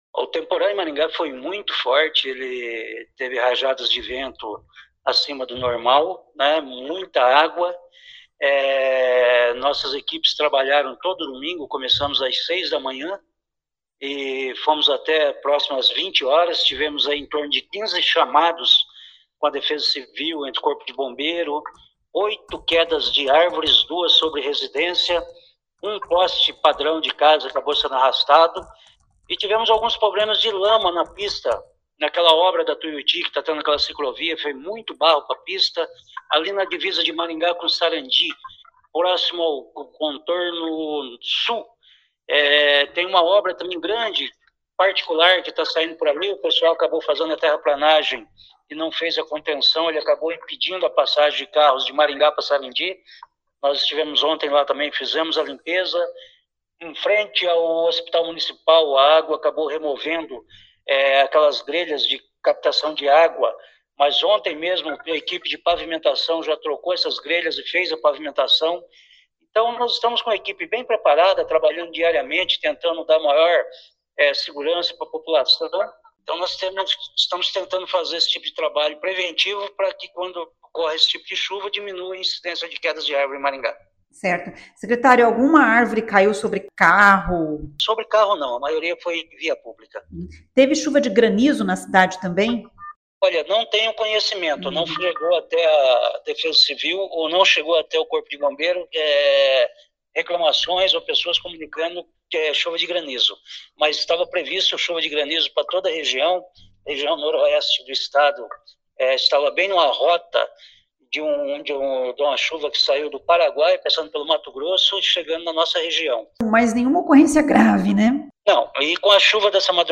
O secretário Vagner Mussio, da Defesa Civil, faz um balanço dos estragos provocados pelo temporal em Maringá no sábado (11) e domingo (12).